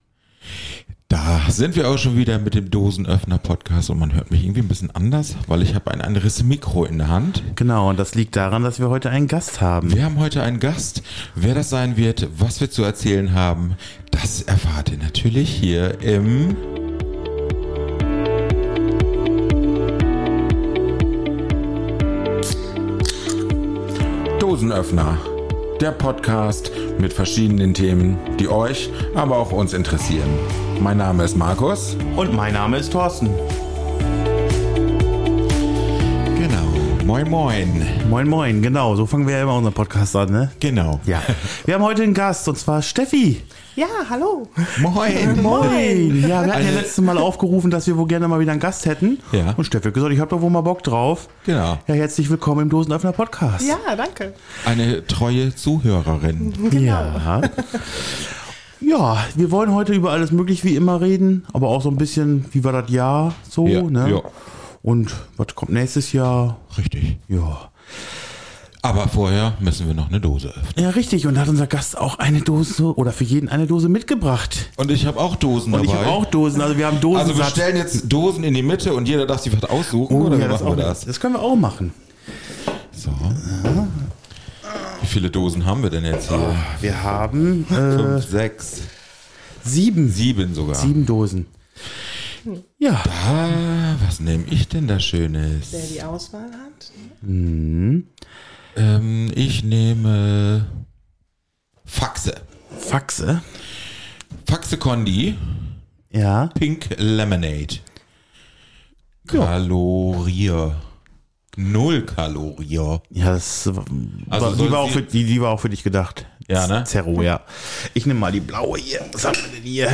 Im warmen Dachgeschoß Studio sind wir heute zu dritt.